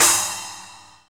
CYM KLB SP0L.wav